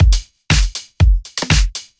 描述：房子放克酷
Tag: 120 bpm House Loops Drum Loops 344.63 KB wav Key : Unknown Mixcraft